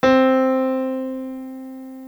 Fichier wav - C moyen
middlec.wav